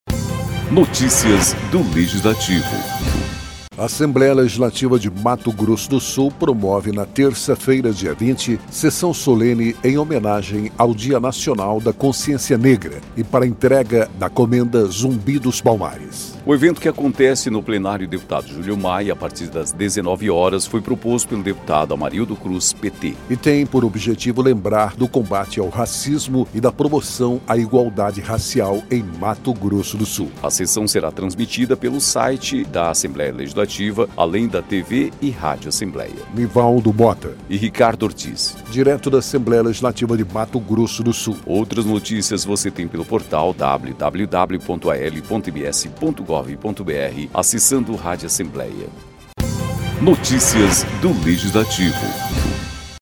Locução